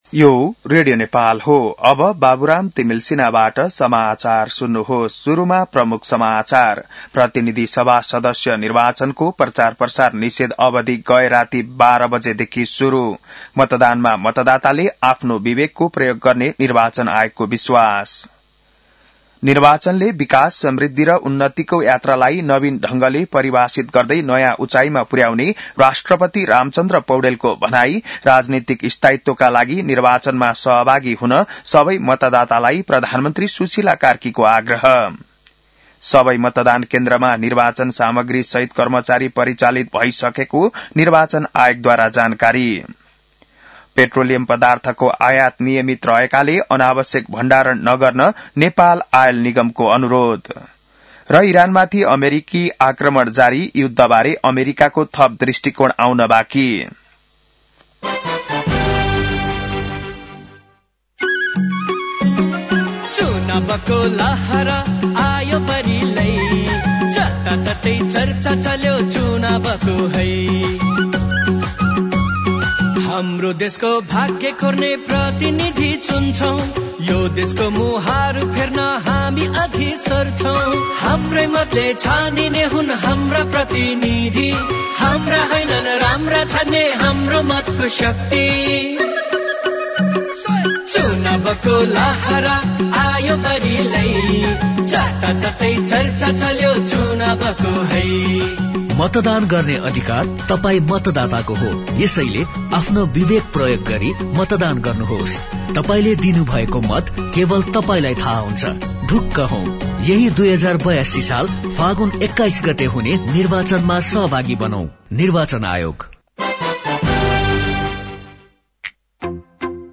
बिहान ७ बजेको नेपाली समाचार : १९ फागुन , २०८२